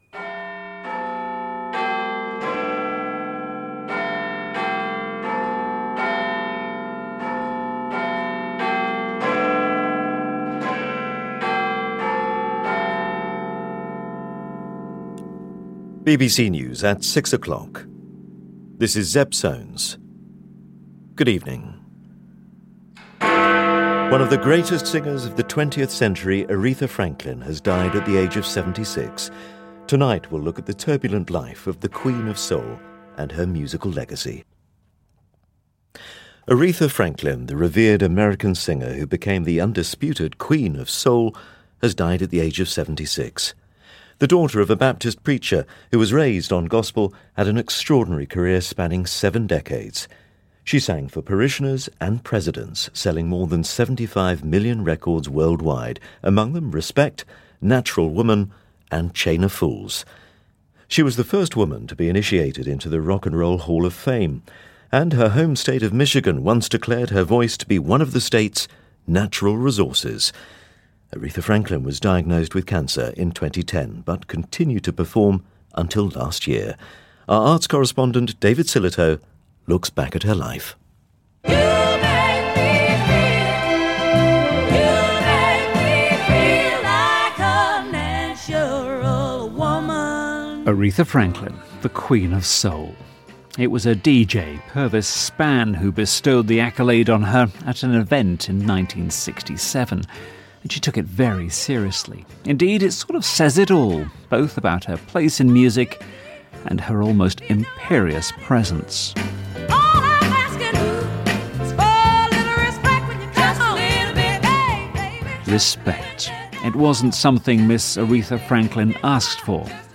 This tribute comes by way of BBC Radio Four who ran it a few hours after her death was announced on the 6 o’clock News.